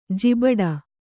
♪ jibaḍa